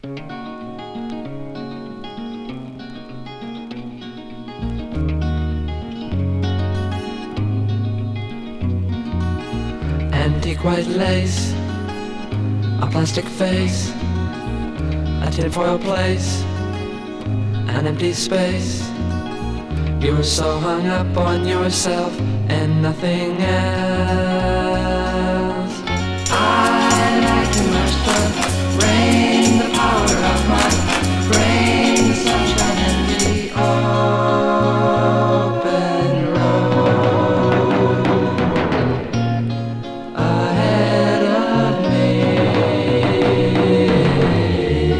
Rock, Psychedelic Rock　USA　12inchレコード　33rpm　Stereo